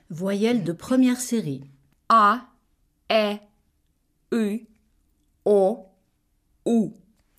Voyelles de première série[1]